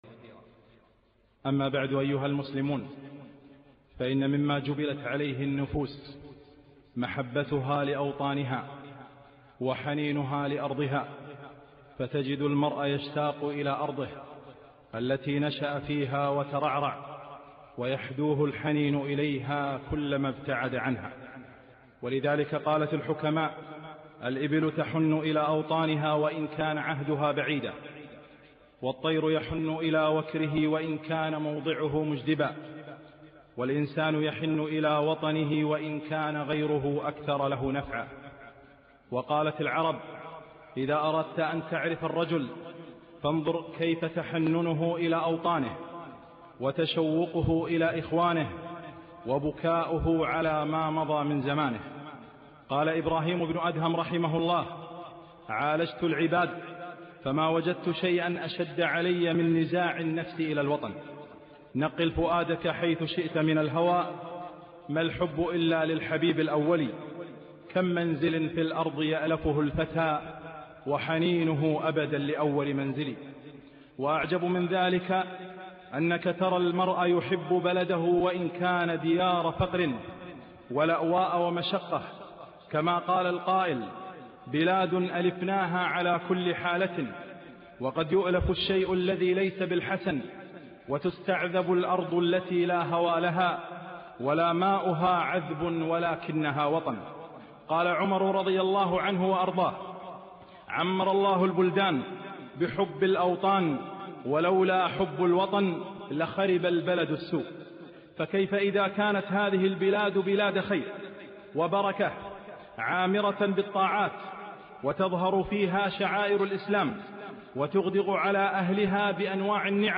خطبة - (كلمة في حق الكويت)